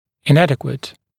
[ɪn’ædɪkwət] [-wɪt][ин’эдикуэт] [-уит]неадекватный, недостаточный, несоответствующий, нарушенный